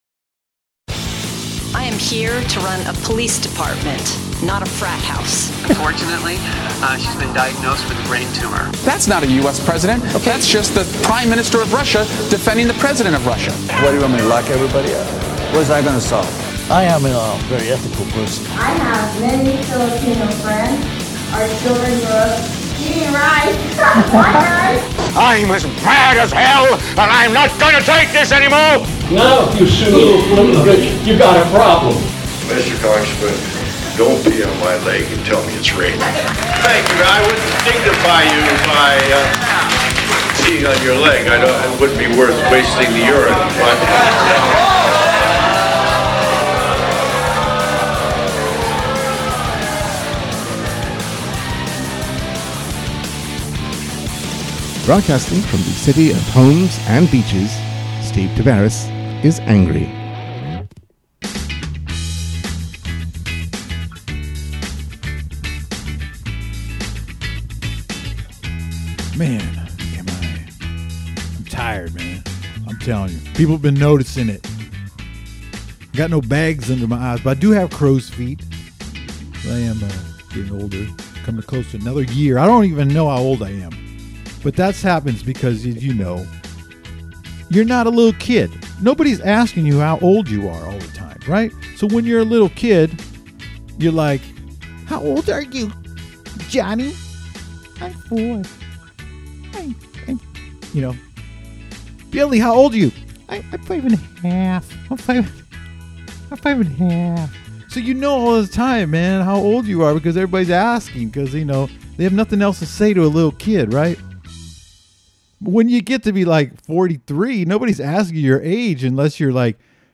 San Leandro Mayor Pauline Russo Cutter sits down for a chat about her re-election campaign, why the city is so kind, and, oh, those roads.